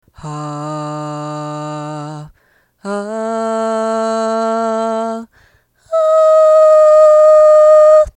まずこの波形は、普通に録音したもの。
左から順に「低音域」「中音域」「高音域（裏声）」の順（うｐ主の音域でです）